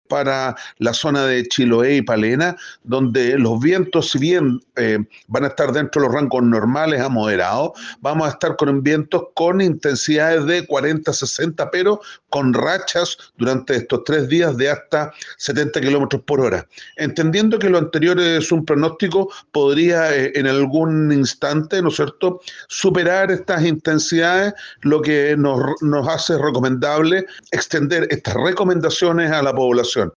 Más de 60 milímetros de agua lluvia y rachas de viento que podrían superar los 100 kilómetros de velocidad a la hora se esperan para las próximas horas en la región de Los Lagos, debido al sistema frontal que atraviesa la zona. Así lo dio a conocer el director regional del organismo, Alejandro Vergués. El personero dijo que para las provincias de Osorno y Llanquihue se esperan fuertes vientos e intensas precipitaciones.